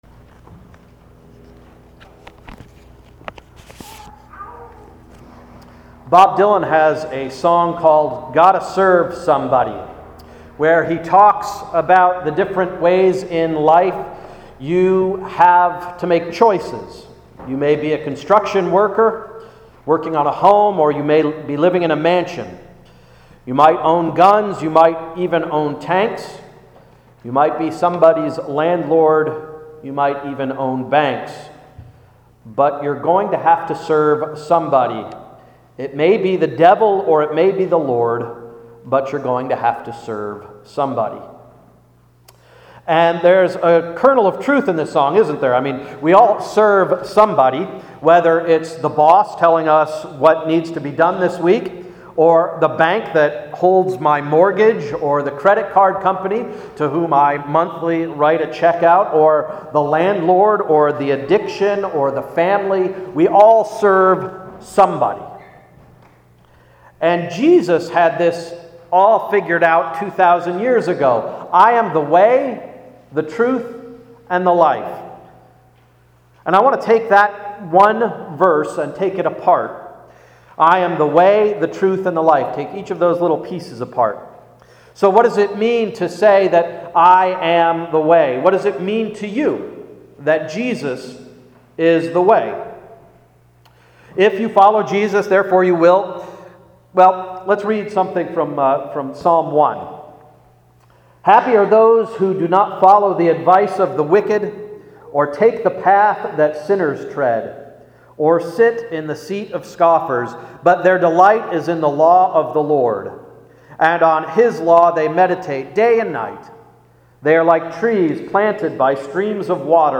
Sermon of May 22–“Way, Truth, Life”